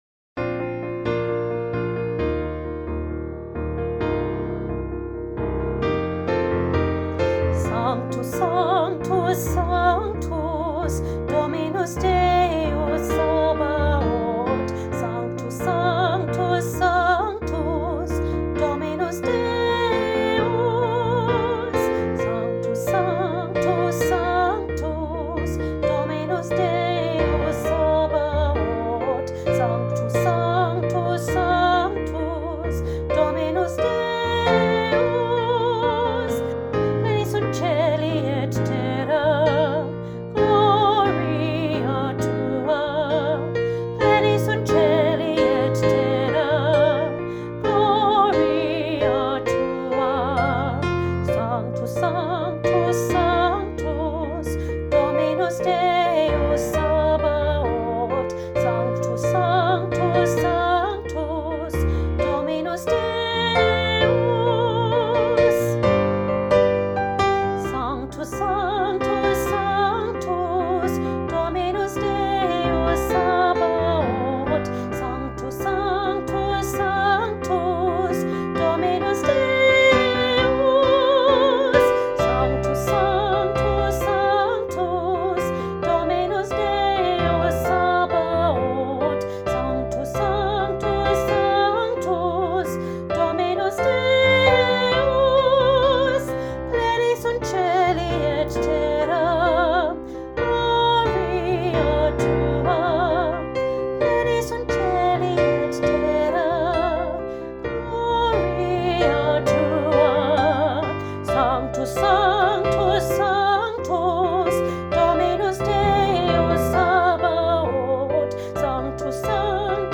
Junior Choir – A Joyful Sanctus, Part 2
Junior-Choir-A-Joyful-Sanctus-Part-2.mp3